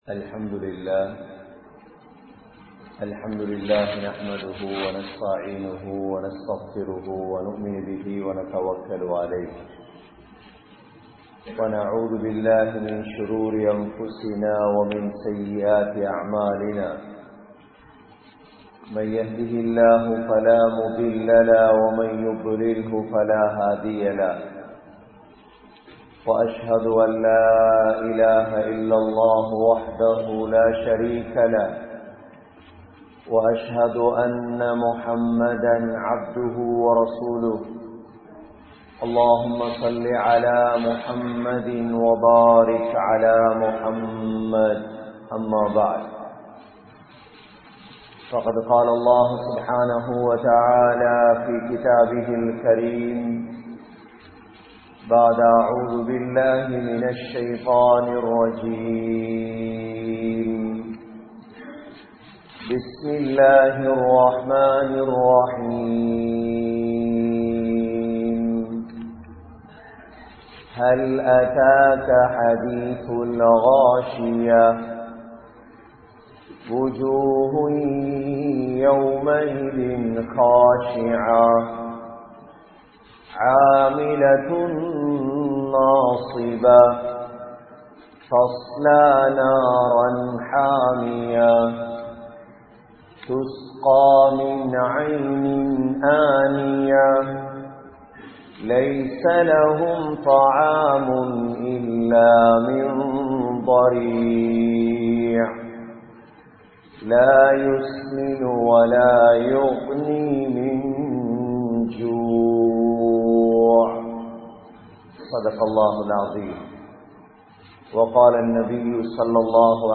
Oru Naal Varavirikkinrathu (ஒரு நாள் வரவிருக்கின்றது) | Audio Bayans | All Ceylon Muslim Youth Community | Addalaichenai
Kurunegala, Jawa Jumua Masjidh